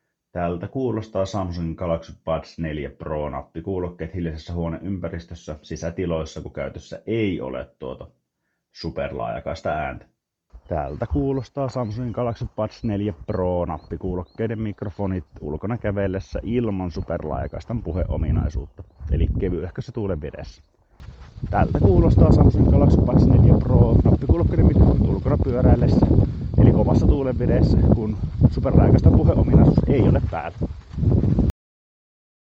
Mikrofonin ääniesimerkki
Mikrofonit Buds4 Proissa tuottavat vakiotilassaan perushyvää ääntä. Sisätiloissa ja kävellessä ääni on jopa erinomaisen selkeää, joskin ulkoilmassa tuuli hieman pääsee puuskahtelemaan läpi puheen sekaan. Pyöräillessä ääni on edelleen selkeä, eikä se muutu robottimaiseksi, sillä kuulokkeet eivät vaikuttaisi vaimentavan tuulta juuri lainkaan. Tämän seurauksena tuuli tuleekin voimakkaasti läpi, mutta ei kuitenkaan peitä puhetta.
Samsung-Galaxy-Buds4-Pro-mikrofonit.mp3